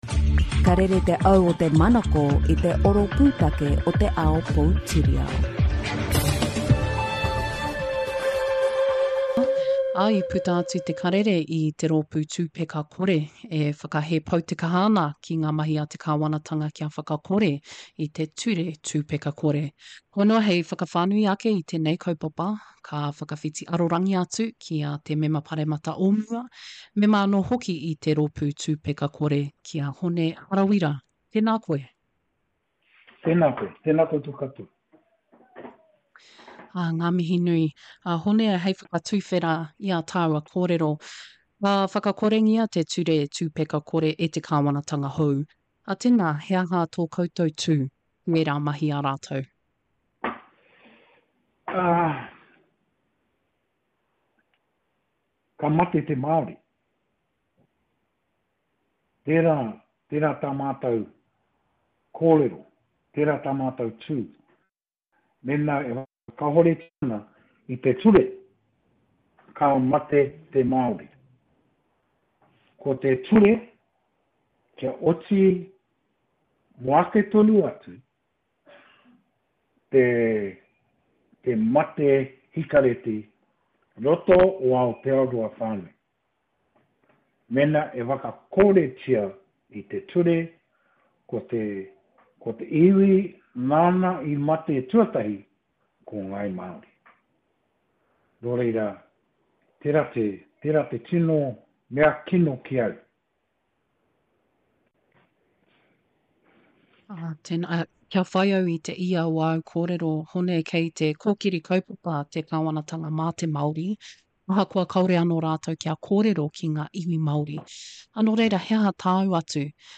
I kōrero mātou ki a Hone Harawira, Mema Paremata o mua, Mema i te rōpū Tūpeka Kore anō hoki.